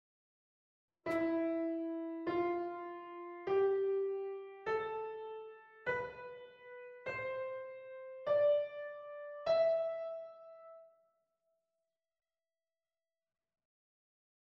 Phrygian Mode Mode
The Phrygian mode is constructed with the intervals from E to E, only using white notes again.
phrygian-mode.mp3